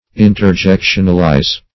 Search Result for " interjectionalize" : The Collaborative International Dictionary of English v.0.48: Interjectionalize \In`ter*jec"tion*al*ize\, v. t. To convert into, or to use as, an interjection.